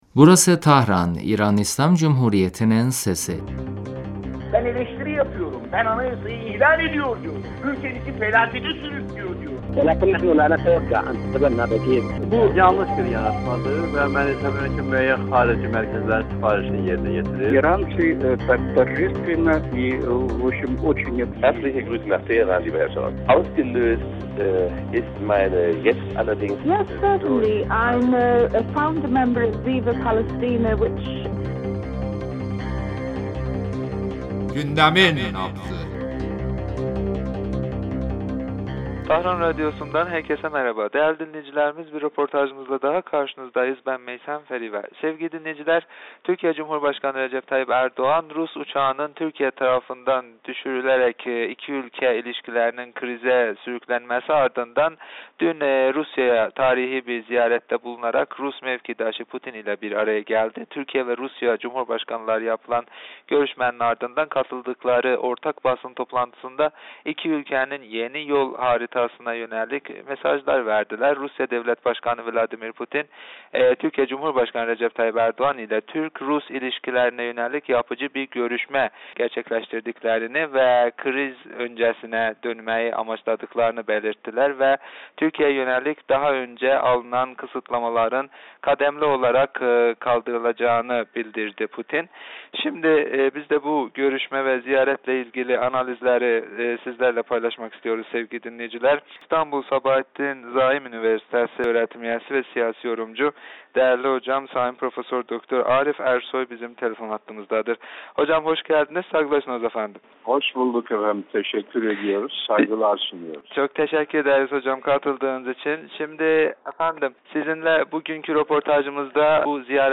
telefon görüşmesinde Ankara - Moskova ilişkileri üzerinde konuştuk.